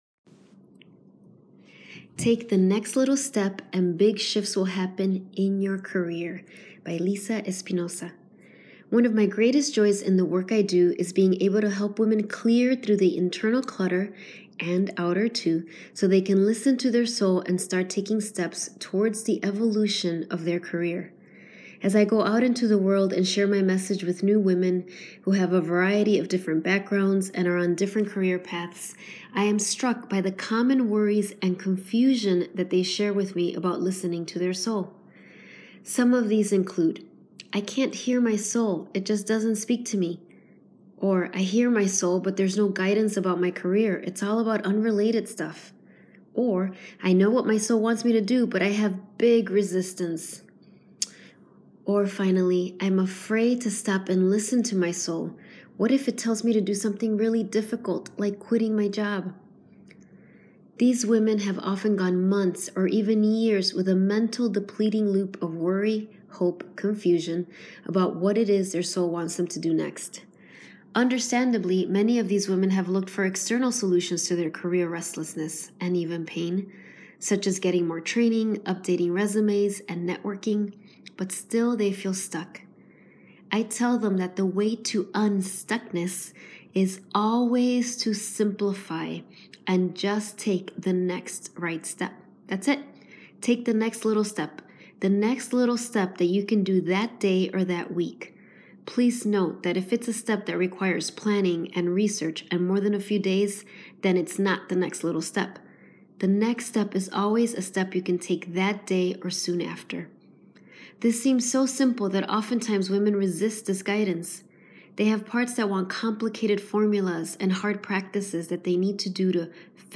Audio version of blog below.